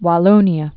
(wä-lōnē-ə)